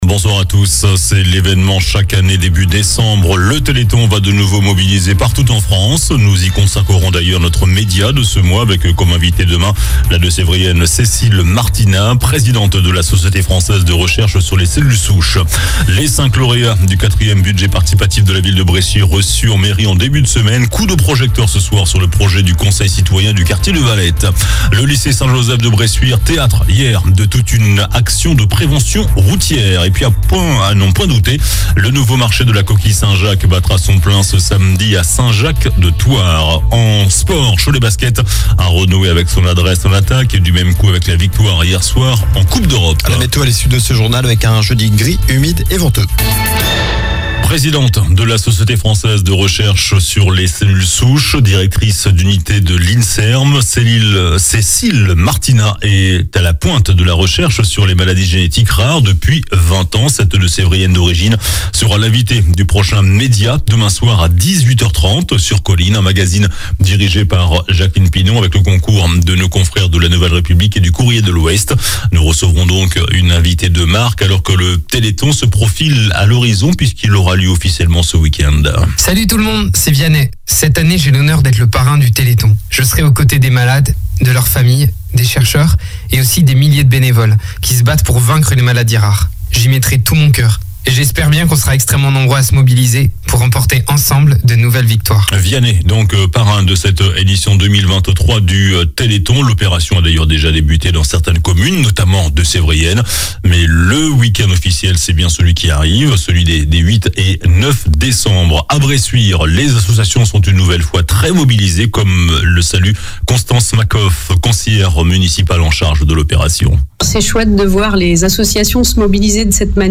JOURNAL DU MERCREDI 06 DECEMBRE ( SOIR )